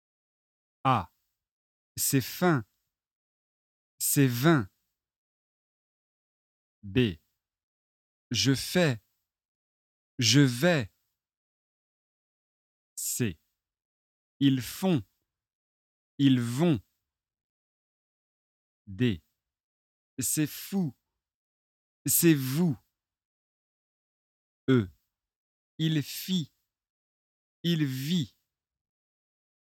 Écoutez et répétez :
f-vpaire-minimale2.mp3